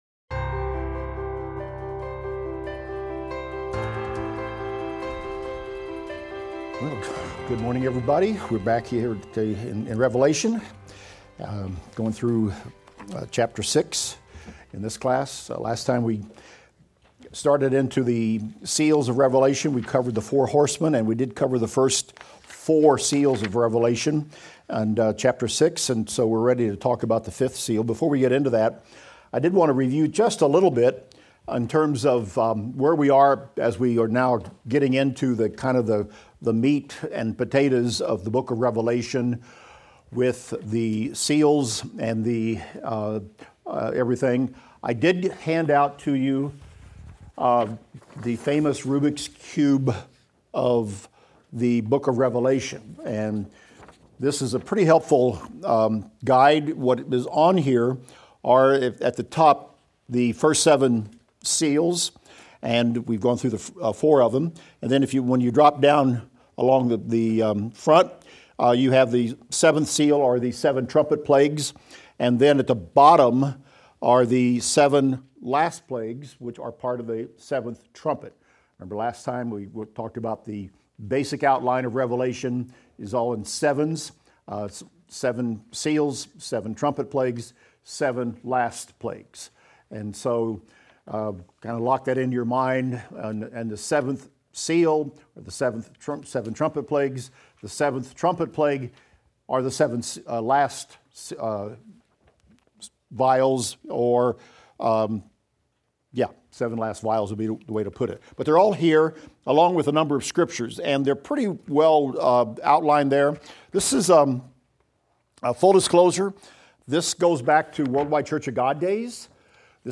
Revelation - Lecture 37 - Audio.mp3